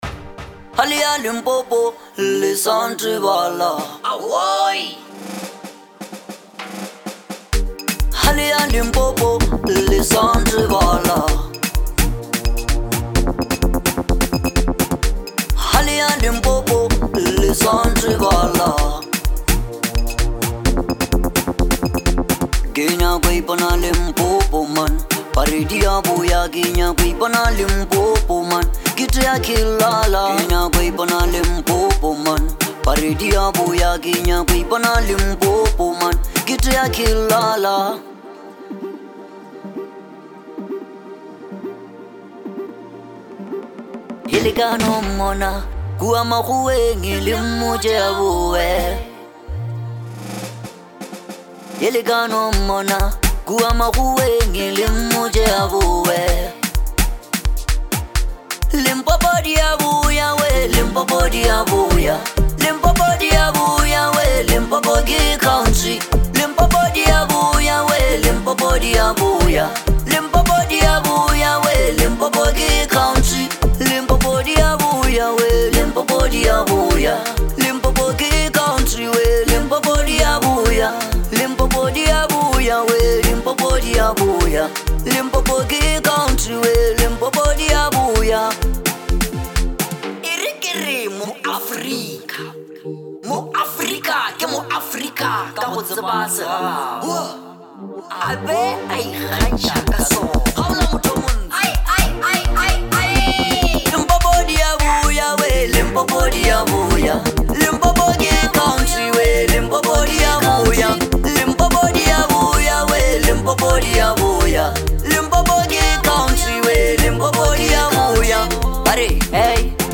Bolo House
The beat is warm, full of energy, and perfect for winter.